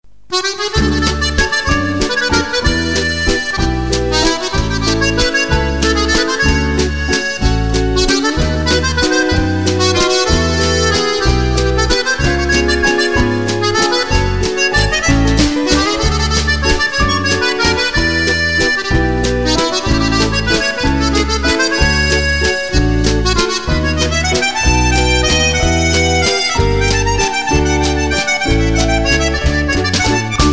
Valse musette